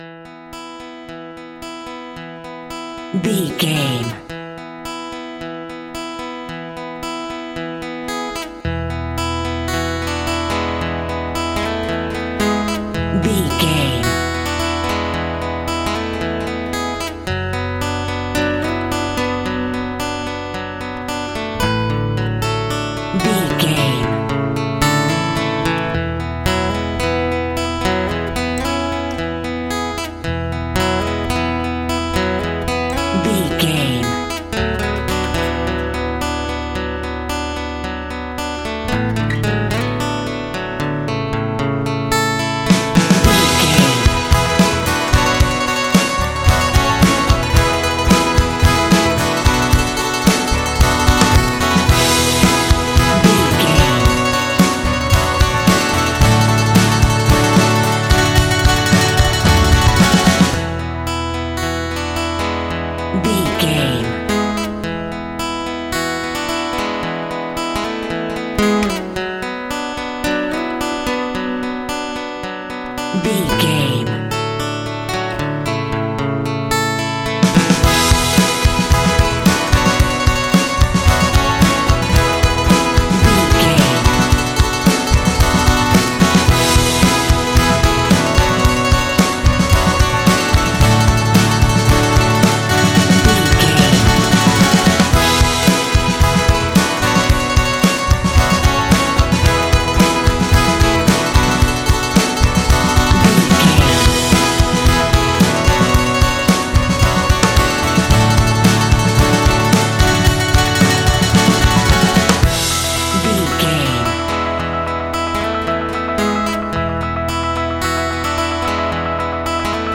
Ionian/Major
romantic
happy
acoustic guitar
bass guitar
drums